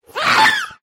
Scream Ghast 3